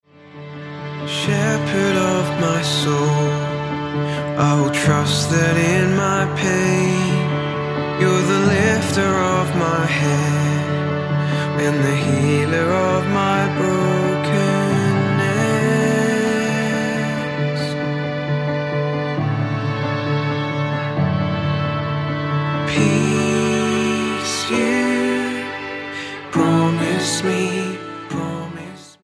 STYLE: Pop
The piano and string-led